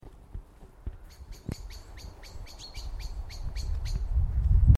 birds